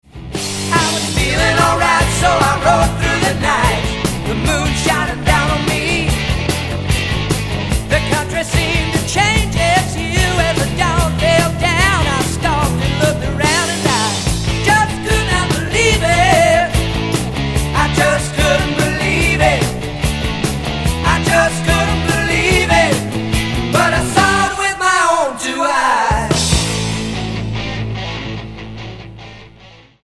Recorded at Soundstage, Toronto.